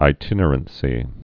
(ī-tĭnər-ən-sē, ĭ-tĭn-) also i·tin·er·a·cy (-ə-sē)